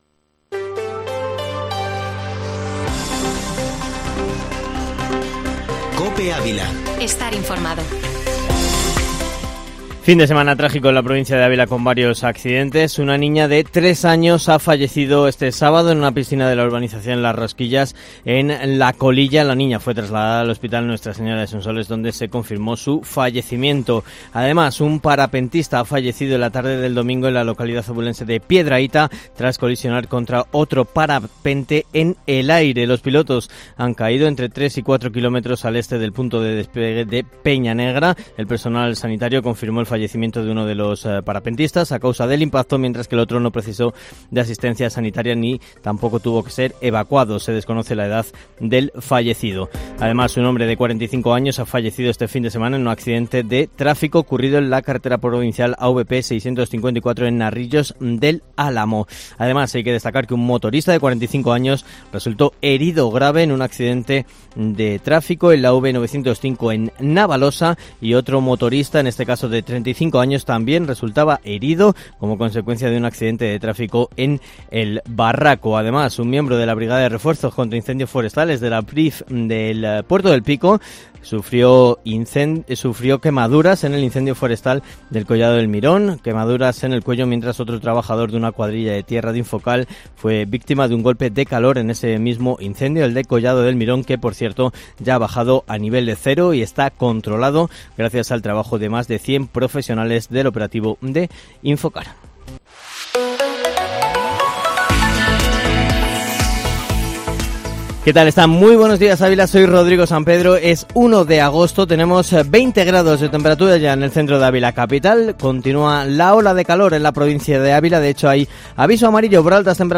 Ávila